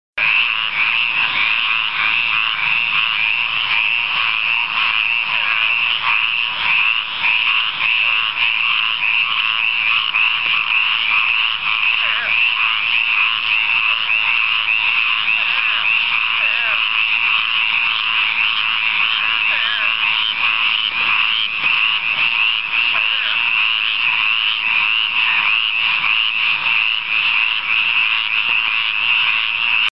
Here's a mixed chorus of little goodies in this clip, featuring Hyla squirrela, and Scaphiopus holbrookii in the background.